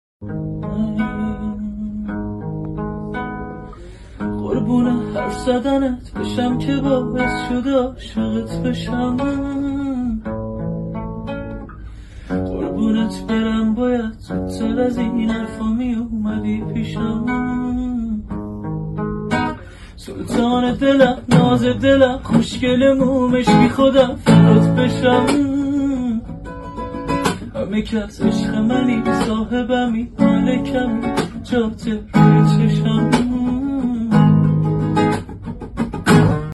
موزیک قربون حرف زدنت با گیتار